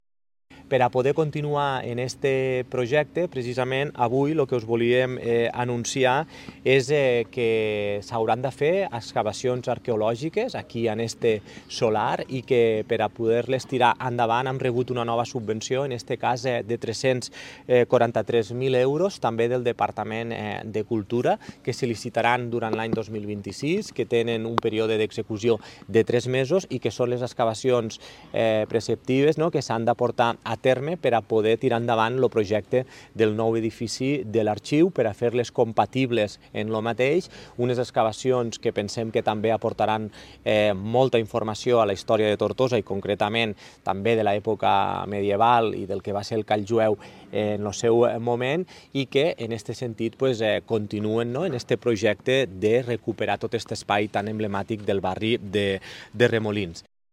L’alcalde de Tortosa, Jordi Jordan
Lalcalde-de-Tortosa-Jordi-Jordan.mp3